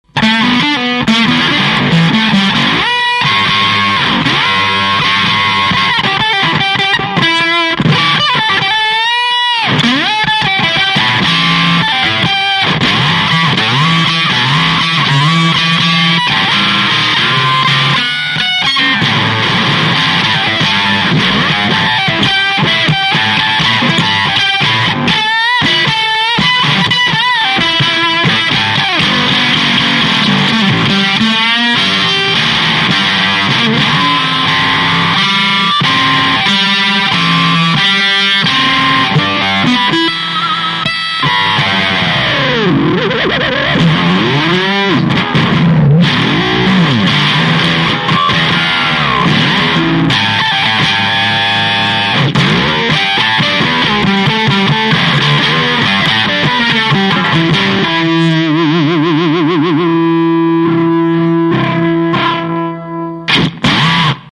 「FUZZ EXPLOSION SAMPLE（510kbMP3)